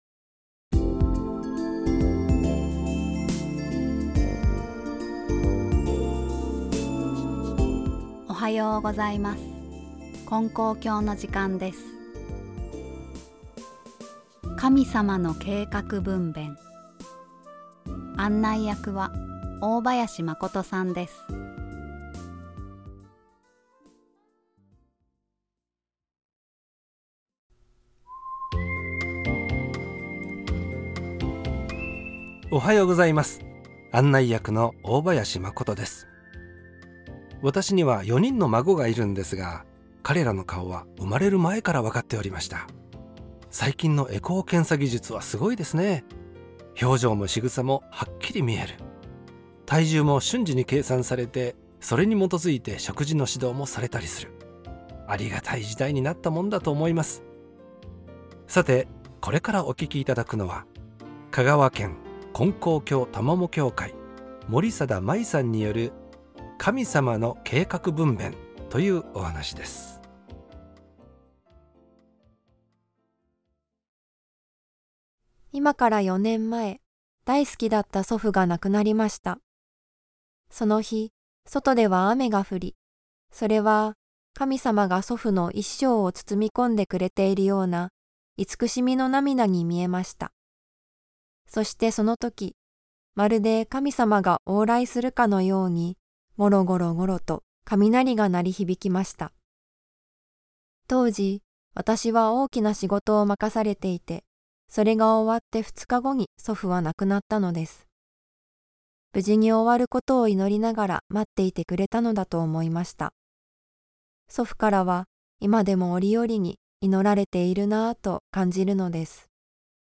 ●先生のおはなし